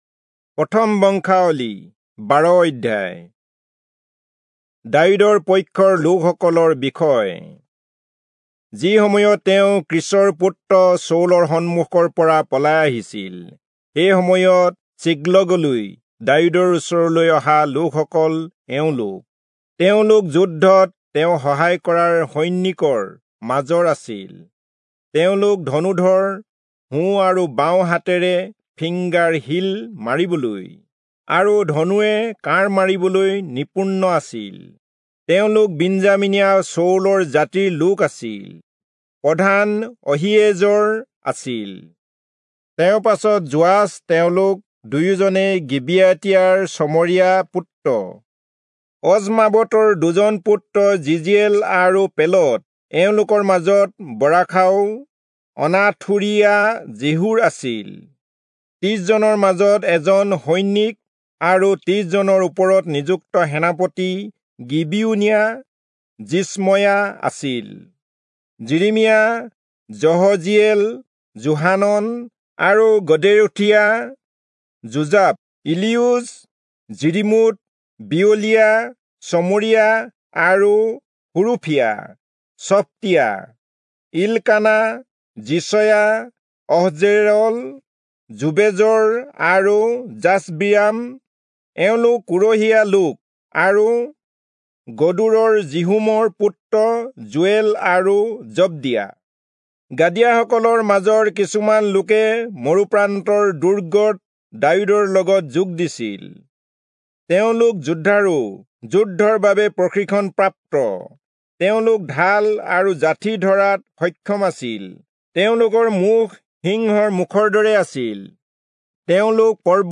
Assamese Audio Bible - 1-Chronicles 24 in Mhb bible version